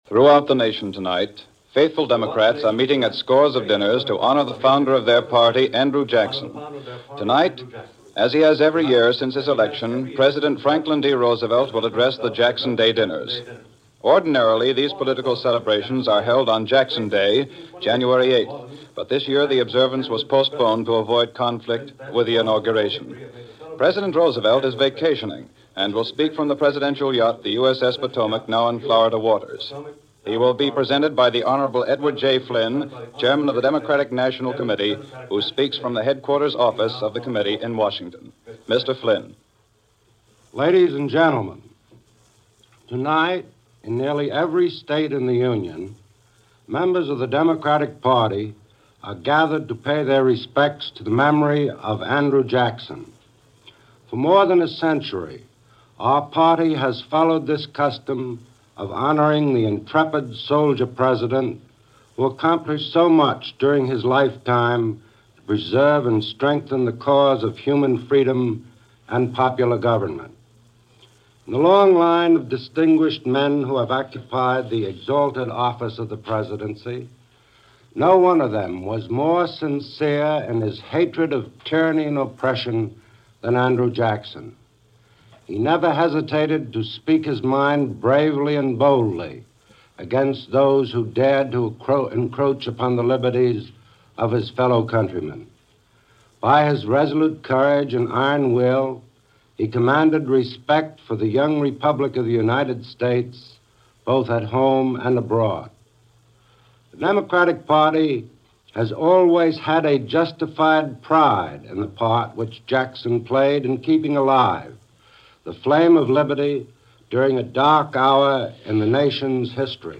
Franklin D. Roosevelt - Jackson Day Address - March 29, 1941 - Past Daily Reference Room - Address at the annual Jackson Day Dinner